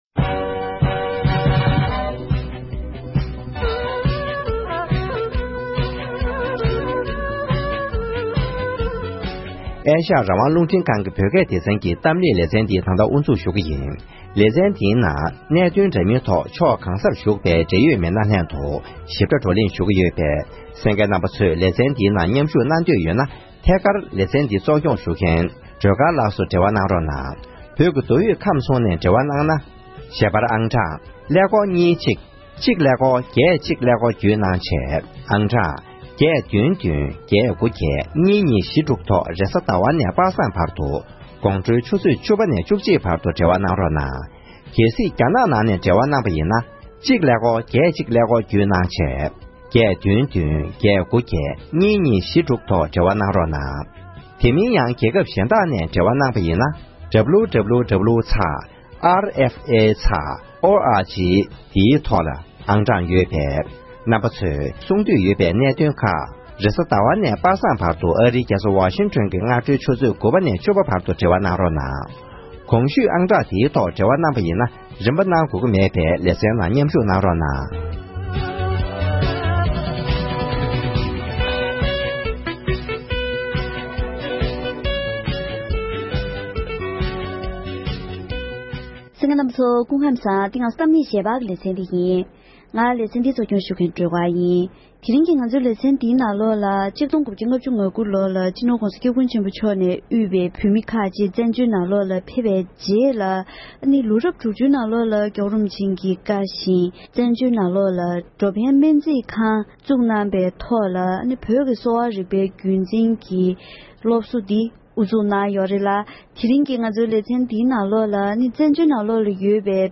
དེ་རིང་གི་གཏམ་གླེང་ཞལ་པར་ལེ་ཚན་